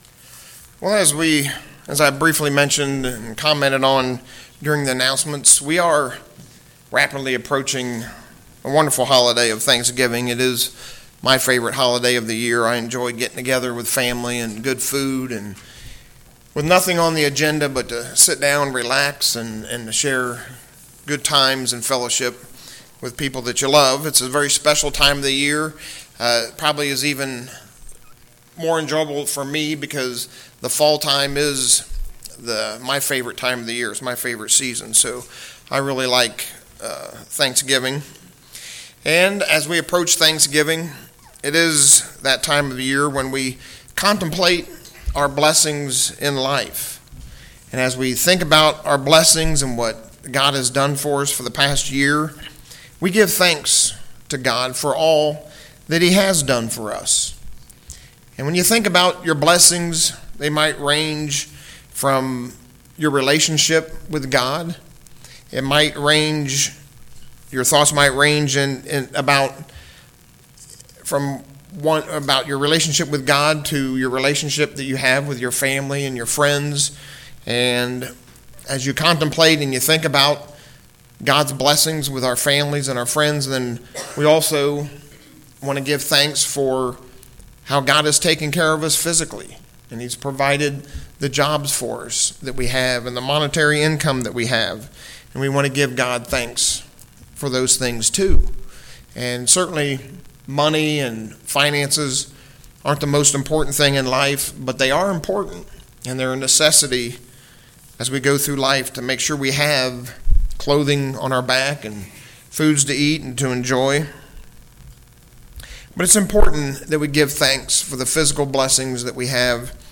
Thanksgiving is a time when we focus on our blessings; our relationships with family and friends, as well as how God has provided for us. This sermon covers four blessings for our congregations.